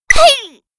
Voiceover Enabled (Default)
combobreak.wav